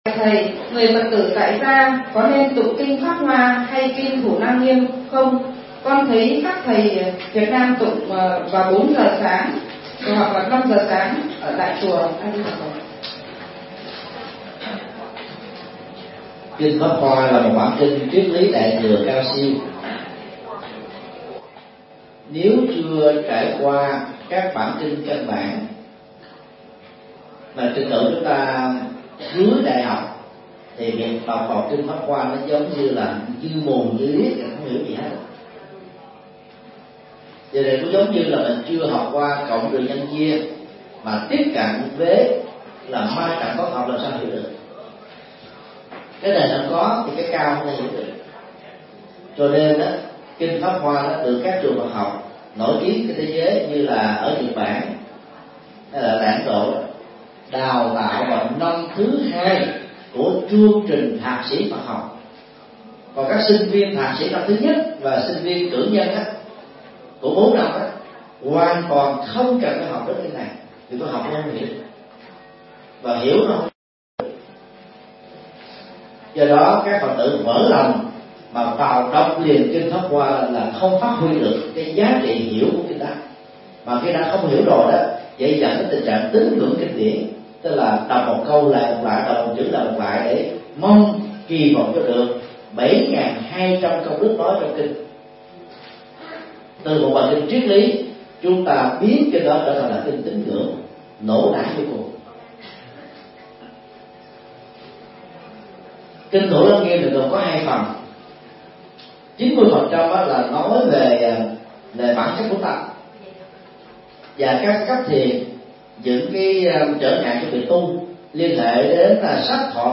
Vấn đáp: Hướng dẫn cách tụng, nghe kinh Đại Thừa